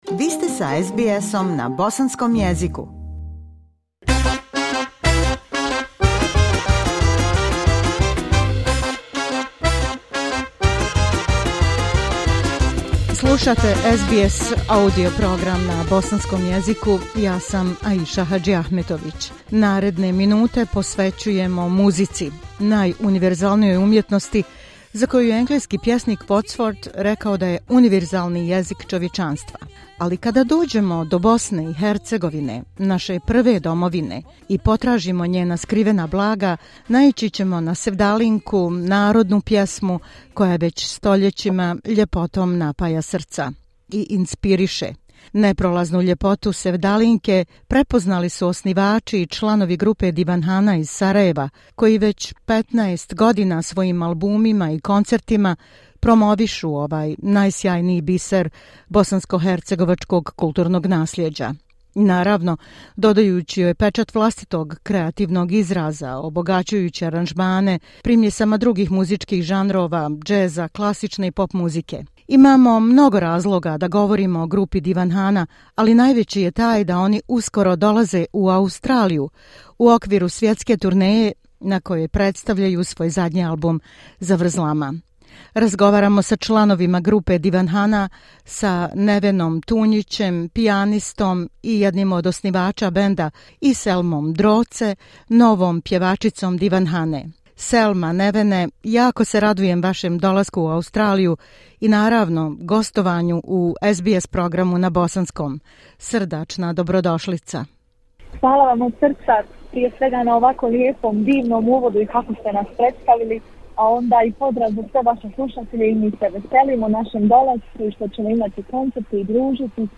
Članovi grupe "Divanhana"